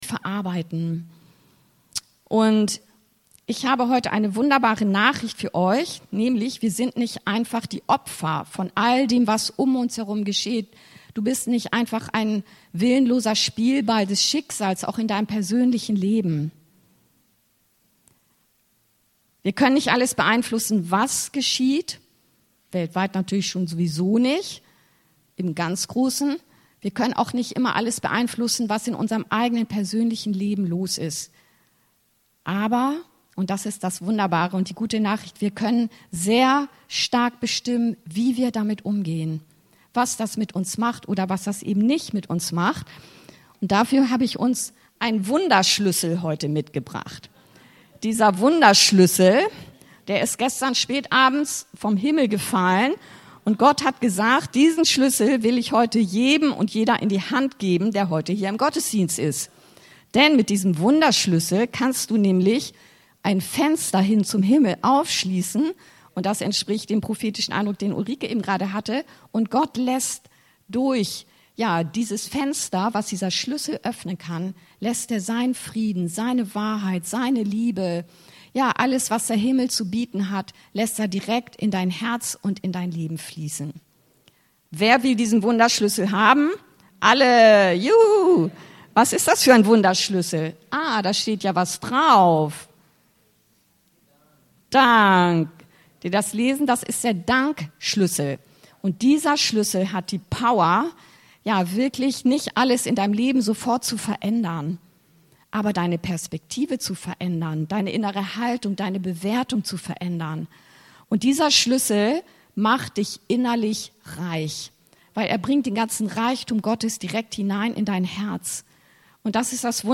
Der Dank-Schlüssel,Eph. 5,20; 2.Kor. 4,15 ~ Anskar-Kirche Hamburg- Predigten Podcast